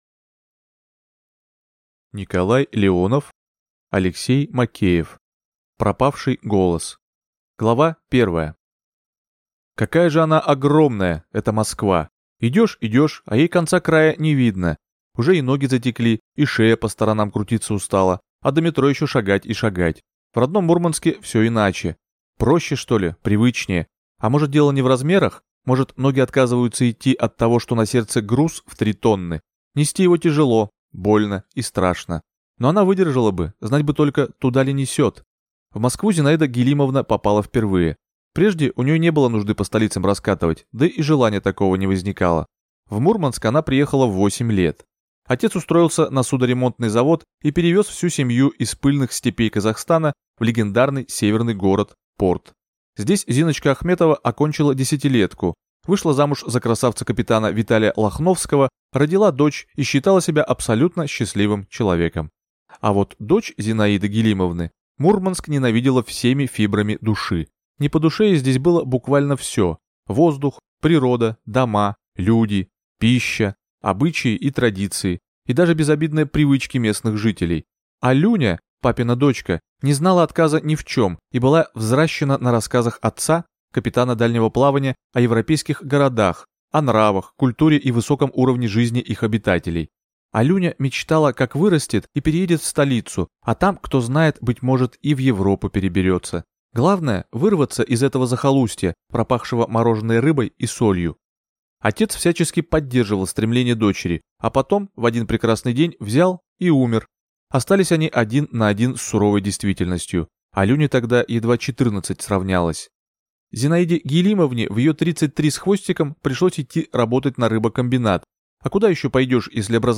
Аудиокнига Пропавший голос | Библиотека аудиокниг
Прослушать и бесплатно скачать фрагмент аудиокниги